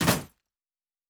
Weapon UI 15.wav